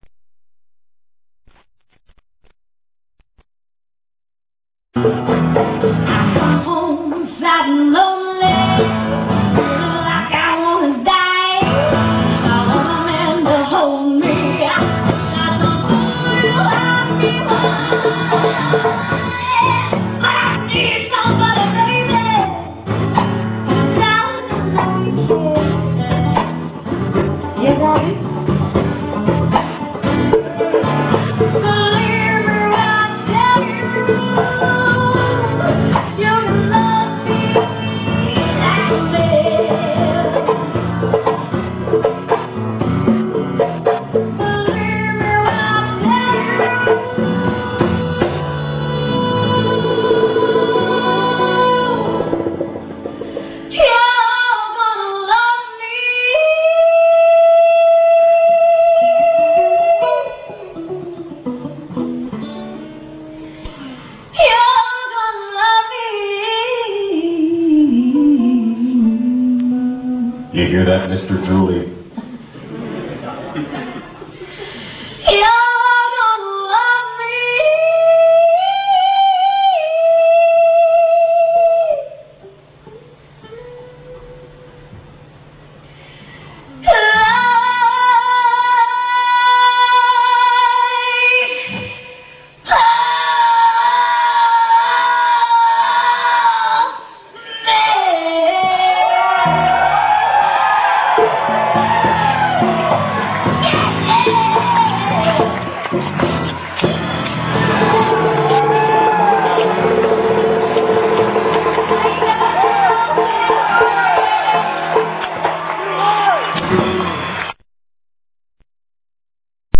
Concert Pictures and Movies